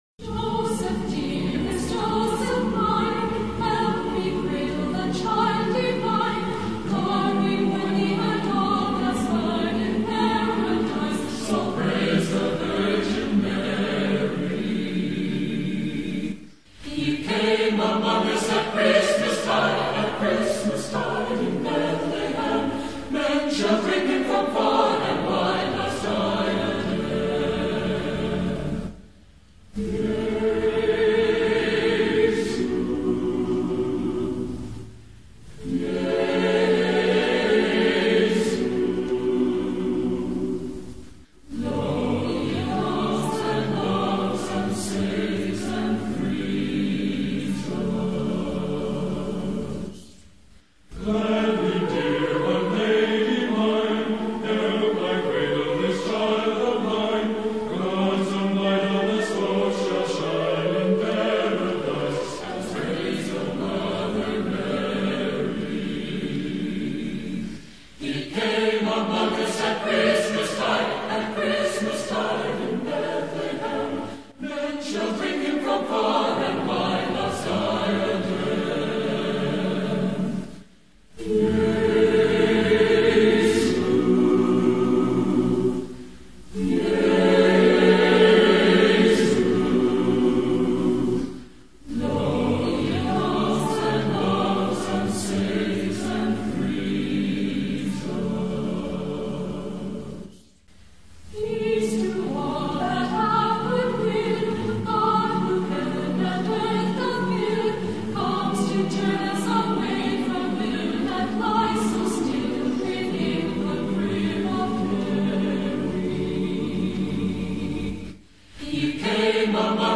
Joseph-Dearest-Lo-He-Comes-and-Loves-and-Saves-and-Frees-Us-German-Christmas-Carol.mp3